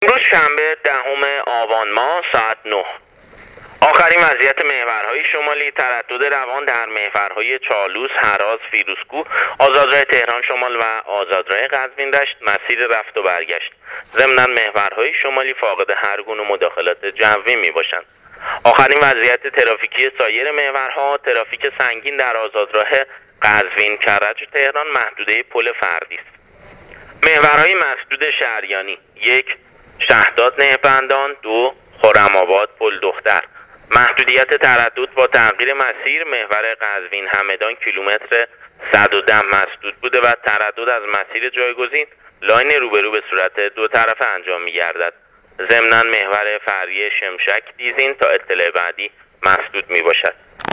گزارش رادیو اینترنتی از وضعیت ترافیکی جاده‌ها تا ساعت ۹ مورخ ۱۰ آبان